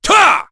Chase-Vox_Attack1_kr.wav